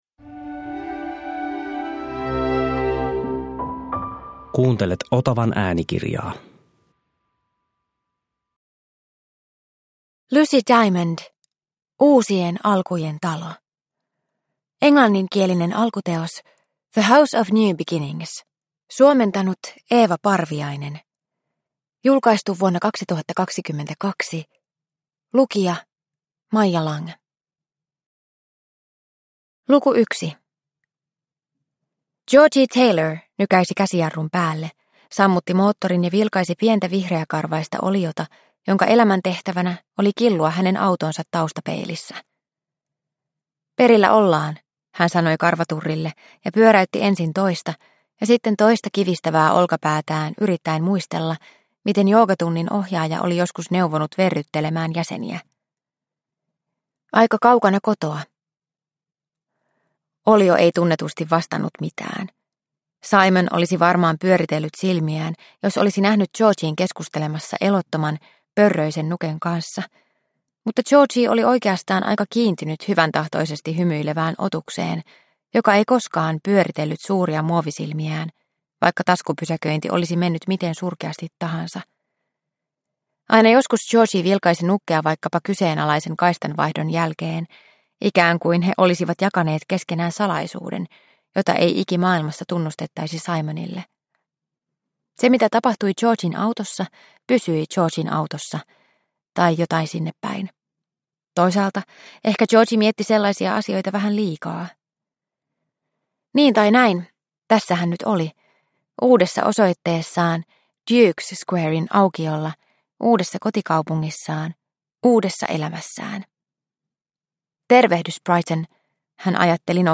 Uusien alkujen talo – Ljudbok – Laddas ner